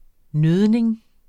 Udtale [ ˈnøðneŋ ]